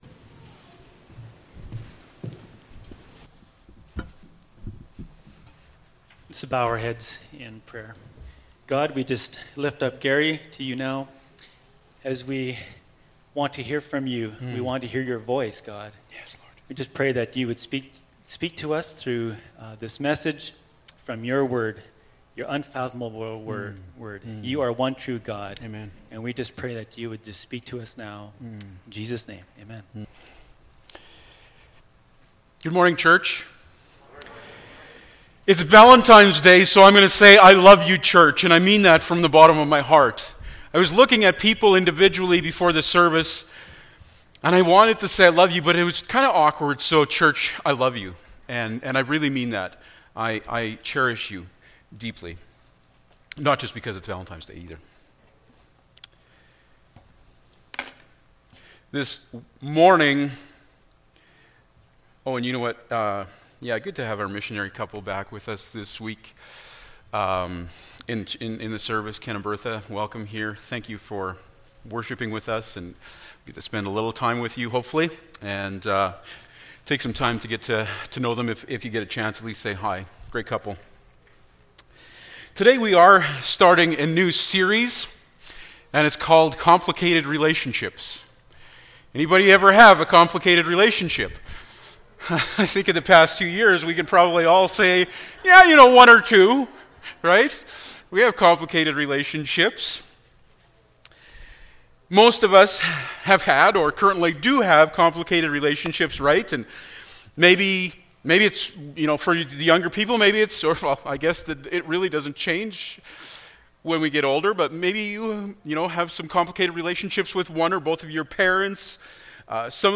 Ephesians 5:21-23 Service Type: Sunday Morning Bible Text